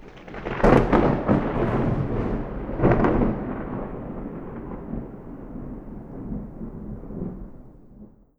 tenkoku_thunder_medium03.wav